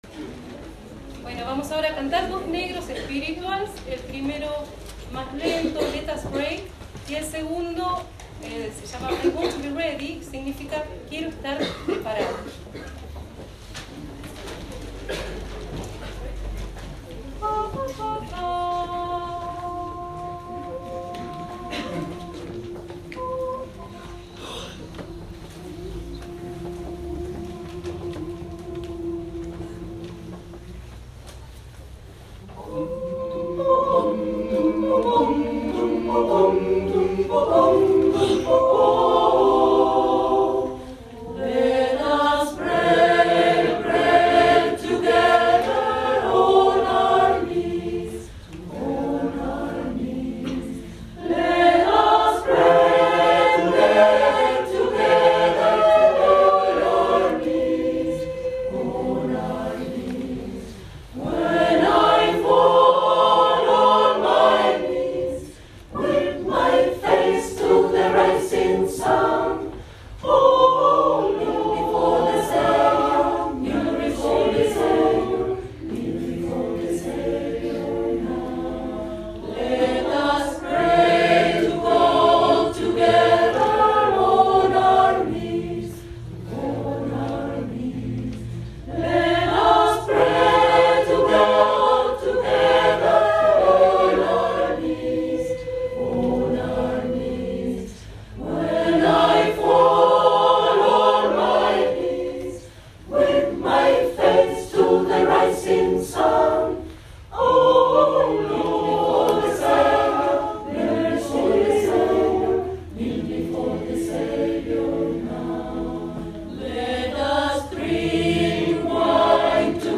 Cantamos en el TEATRO SAN JOSÉ
Negro Spiritual